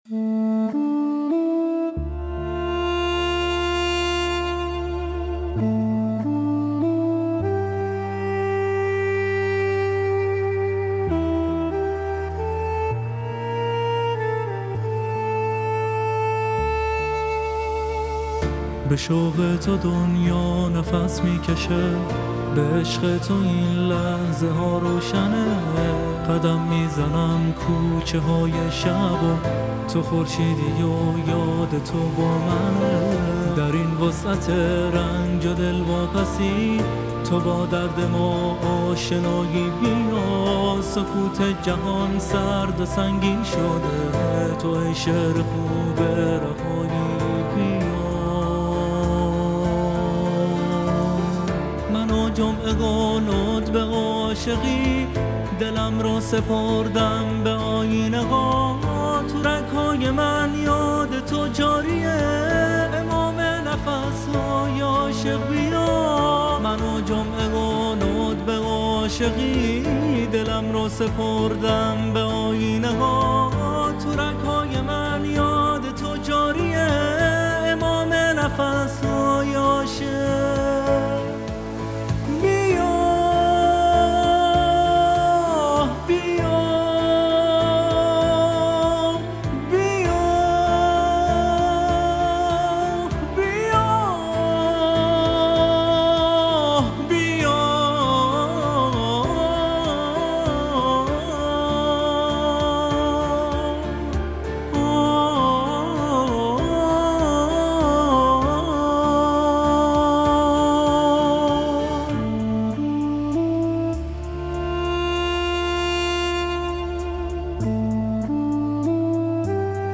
نماهنگی